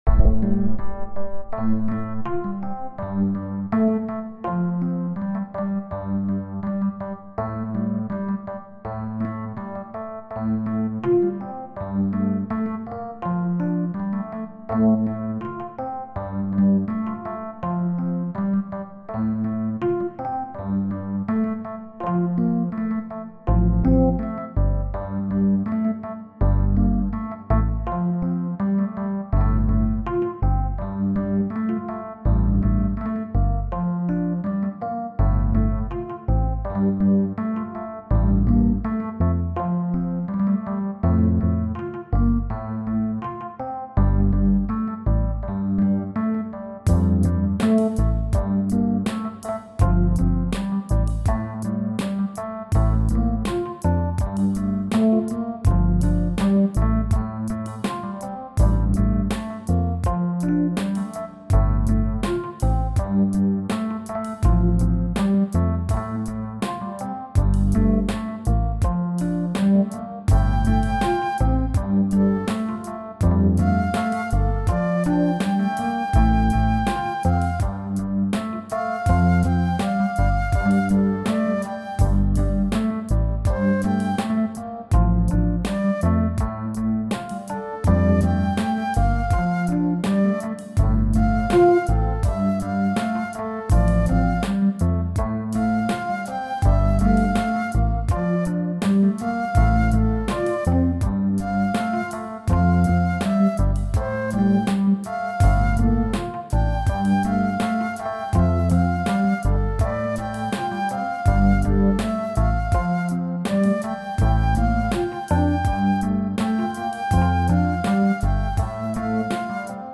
Brumenn est un duo piano électrique/flute alors que nijal est un morceau ambient/New age.
Henon ( 1.7 - 0.6) Sol (G) Dorien 82